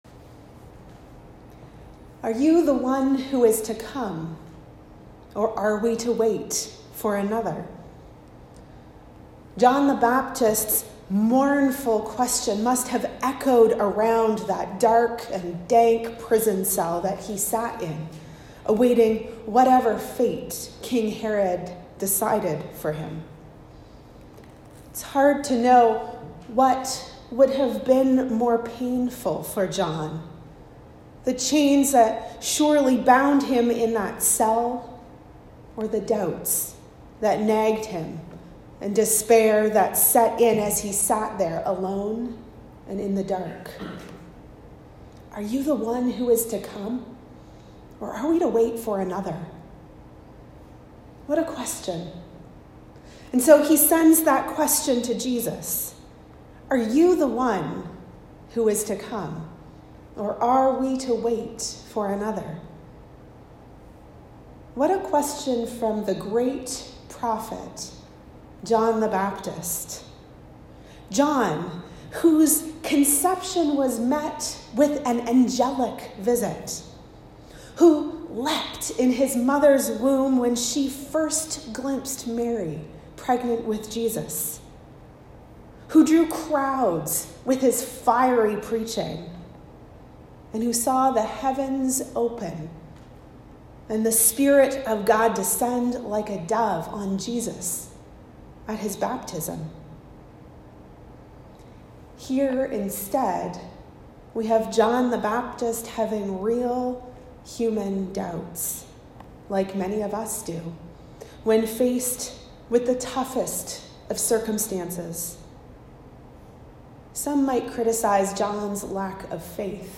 Sermons | Parish of the Valley
Recorded at St Anthony's, Whitney.